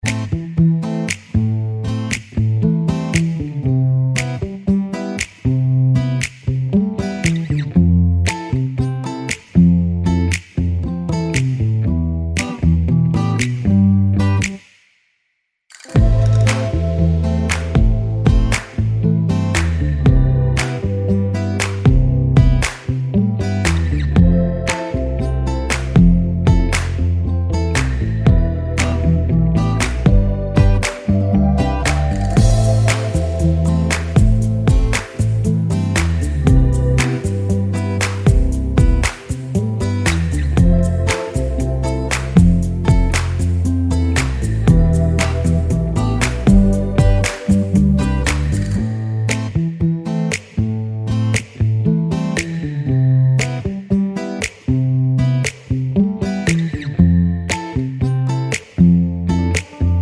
Duets